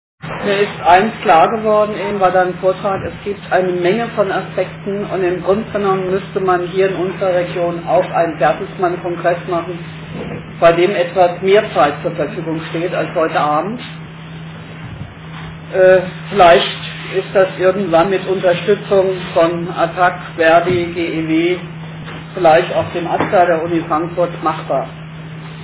Attac-AG "Privatisierung Nein!", GEW BV-Ffm, ver.di FB 5 (Ffm), Jusos Ffm: hatten ins Gewerkschaftshaus eingeladen.
Über 80 Besucher erlebten einen interessanten Abend.